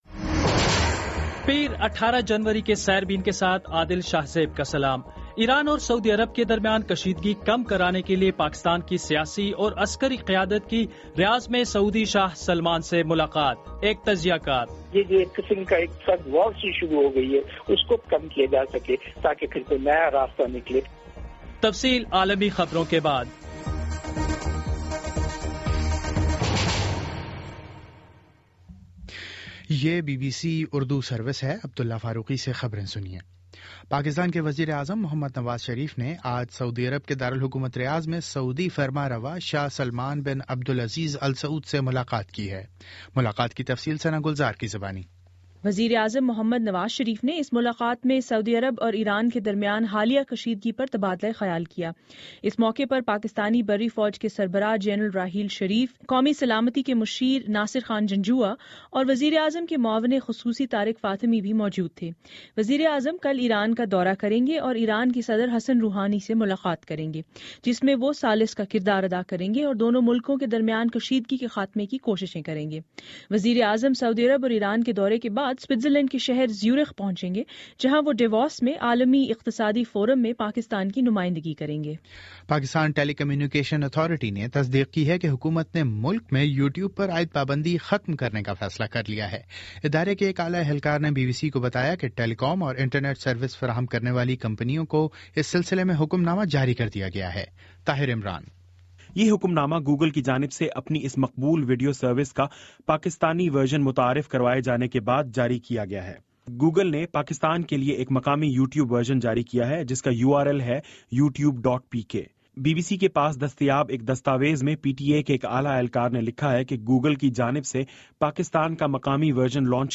دس منٹ کا نیوز بُلیٹن روزانہ پاکستانی وقت کے مطابق شام 5 بجے، 6 بجے اور پھر 7 بجے۔